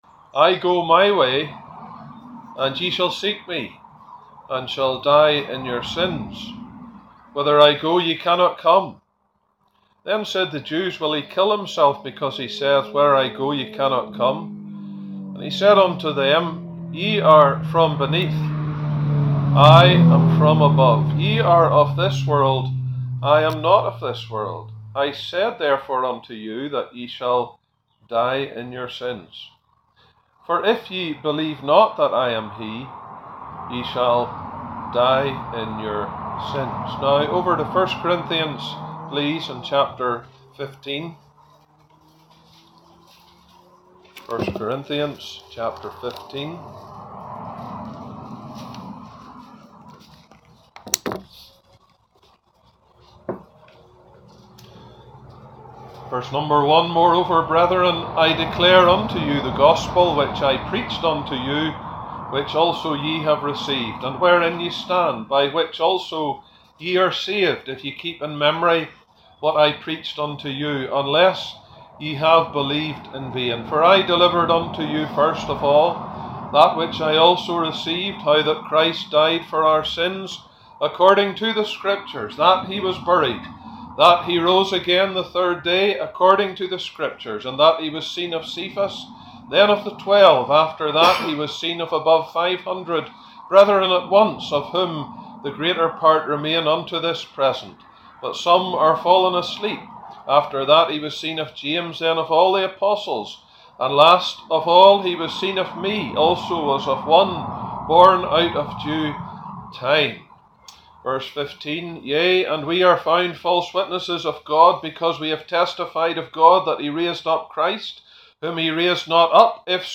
He explains on the Warnings of Christ and finishes with the Work of Christ. A very challenging gospel message. Remember the most lethal silent killer is not a heart attack or stroke but the sin of unbelief!